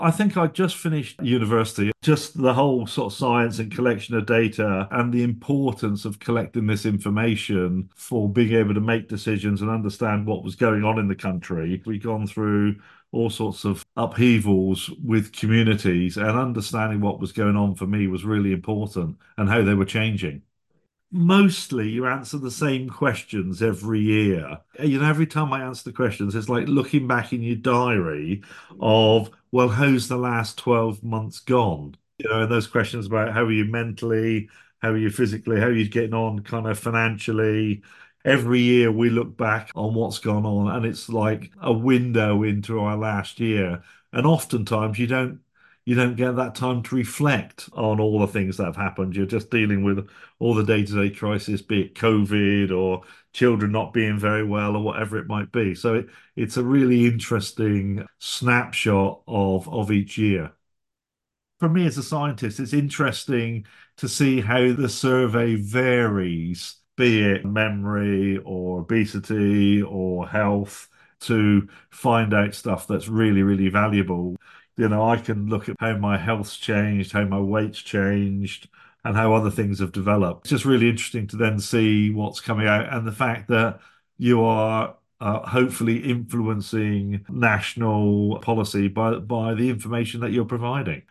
Sixty Stories Podcast - University of Essex Interview with Understanding Society participant Oct 31 2024 | 00:01:31 1x 00:00 / 00:01:31 Subscribe Share Spotify RSS Feed Share Link Embed